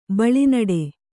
♪ beḷu nuḍi